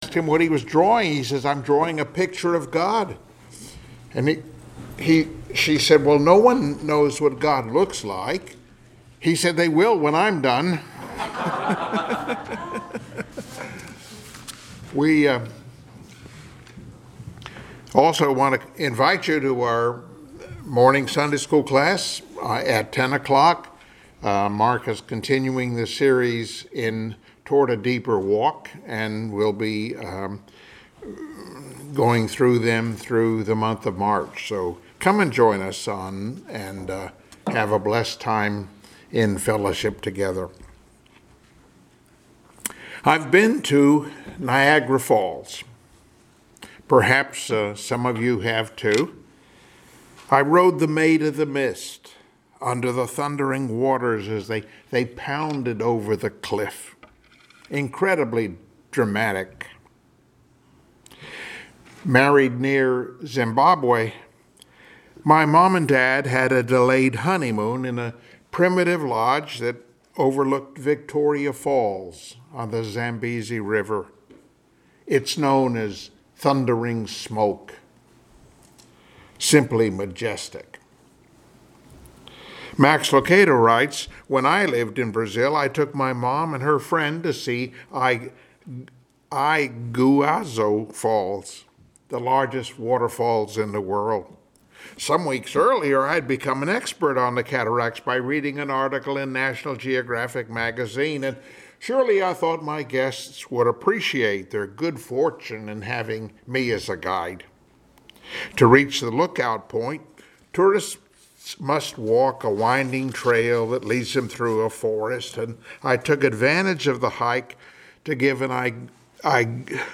Passage: Job 38 Service Type: Sunday Morning Worship Topics